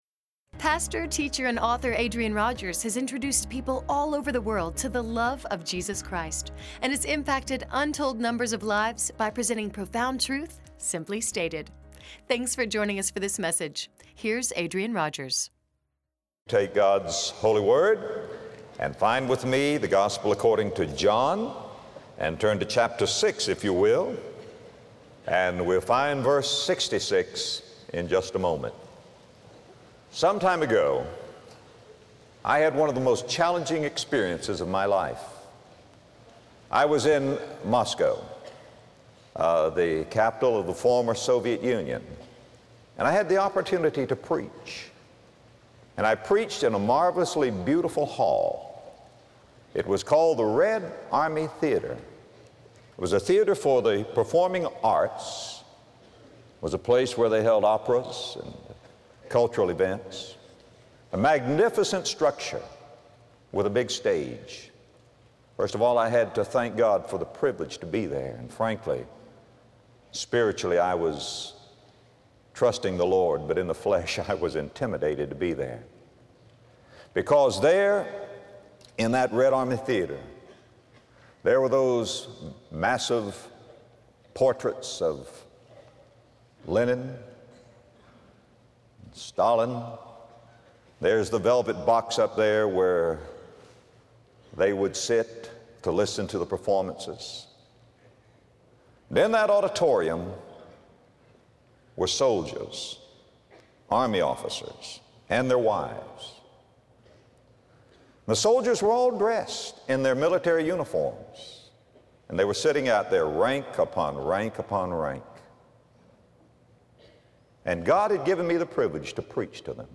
If you were given the chance to speak to someone who has never known the love of God, what would you say? In this message, Adrian Rogers gives powerful and practical insight on how to boldly share your faith in Jesus Christ with others.